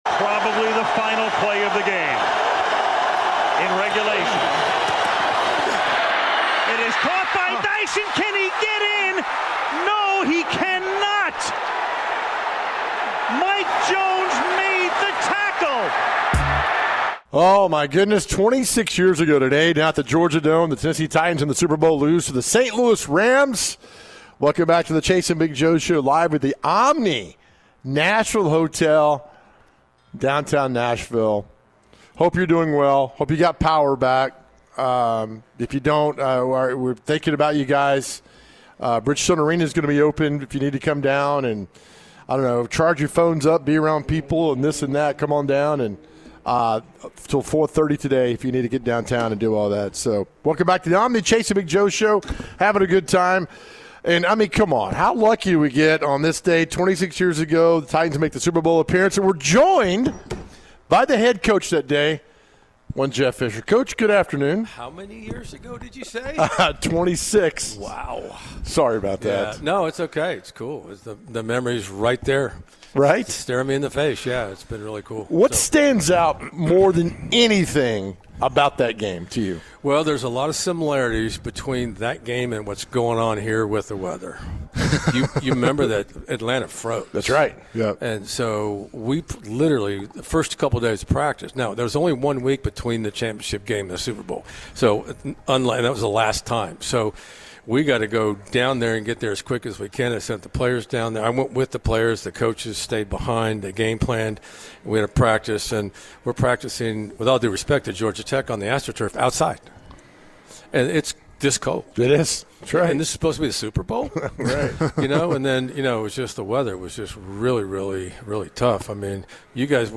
Former Tennessee Titans HC Jeff Fisher joined the show and was asked about Super Bowl 34. Would Jeff Fisher have gone for it if they scored on the last play of the game?